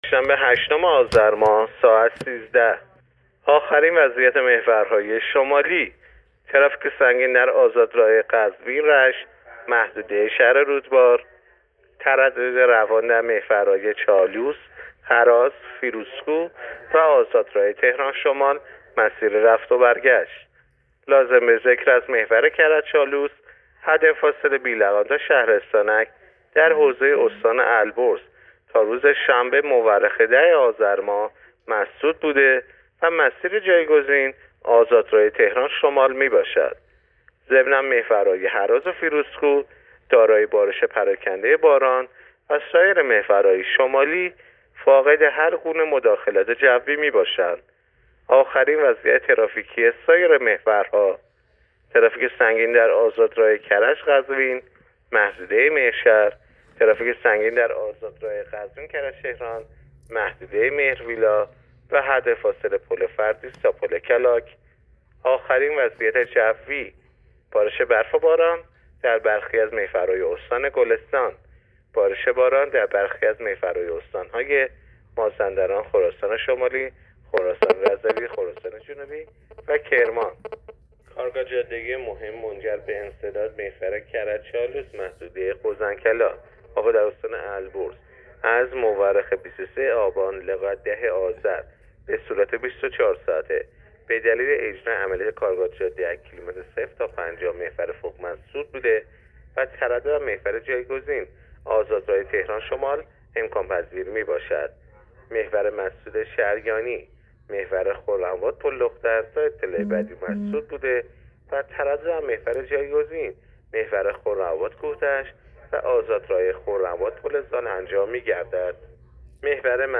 گزارش رادیو اینترنتی از آخرین وضعیت ترافیکی جاده‌ها تا ساعت ۱۳ هشتم آذر؛